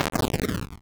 Hit sound file –
HitSound.wav